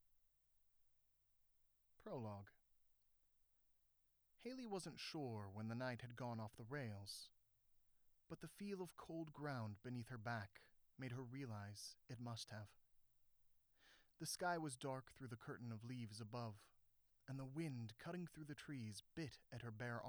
There must be some badly shielded wiring in the walls around me somewhere, so when I turn the power off to that part of the house, I get a cleaner sound.
I have a raw sample and one that I applied EQ/Noise Reduction/RMS Normalize/Limiter to, I really appreciate this,
Most of it is gone and you can sop up the rest with Noise Reduction.